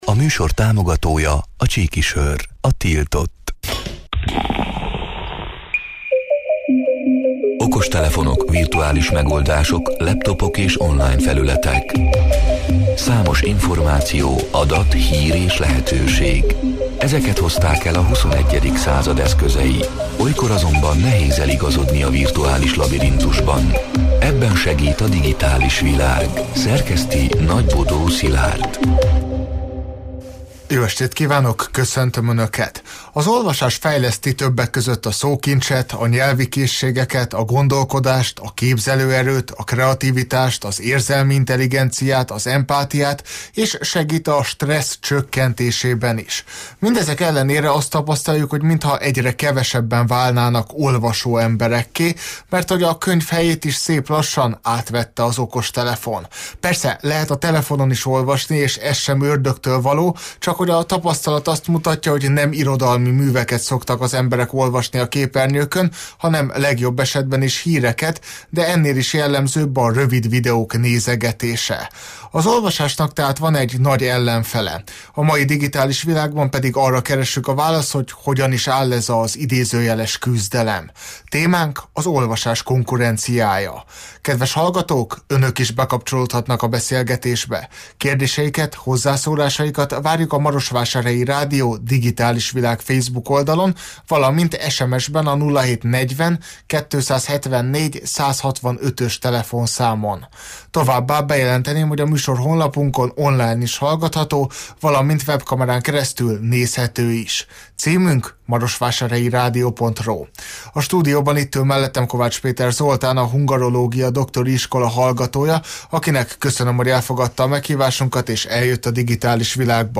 A Marosvásárhelyi Rádió Digitális Világ (elhangzott: 2025. július 29-én, kedden este nyolc órától élőben) c. műsorának hanganyaga: